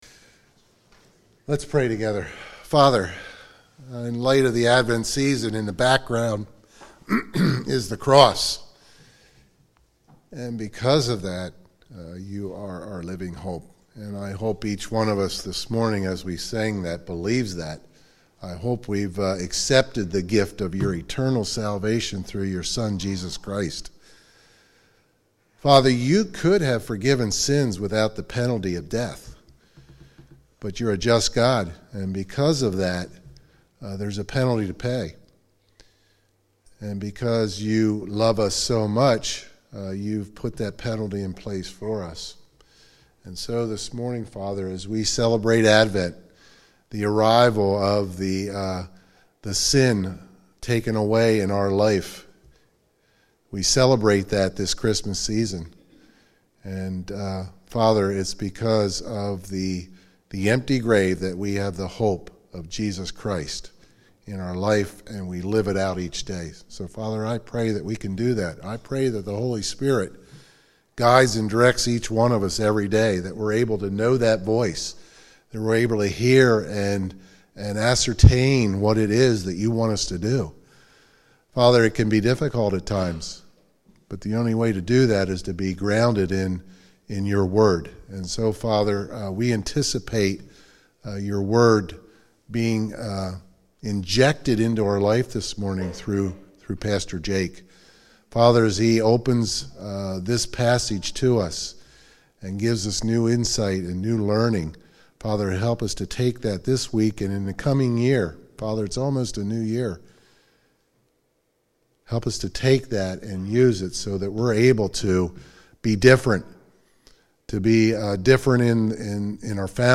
SERMONS » Conestoga Bethel